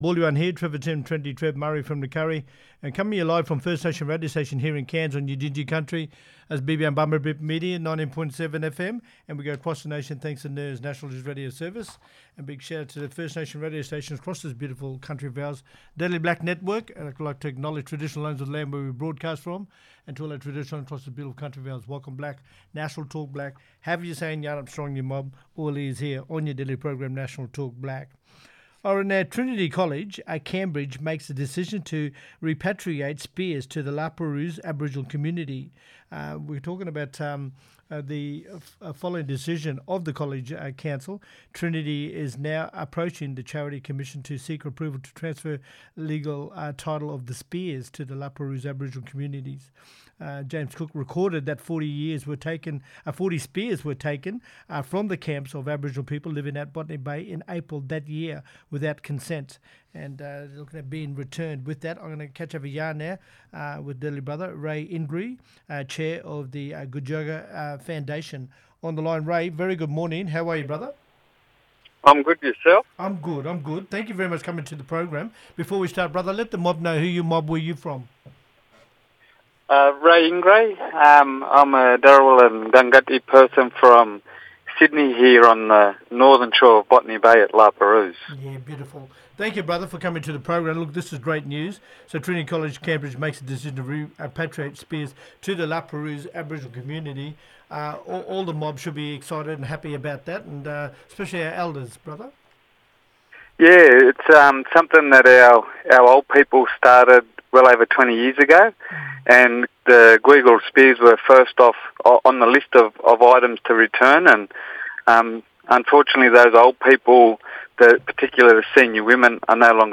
Corrine MacMillan, Member for Mansfield and Chair of the Community Support and Services Committee, talking about the Path to Treaty Bill for 2023.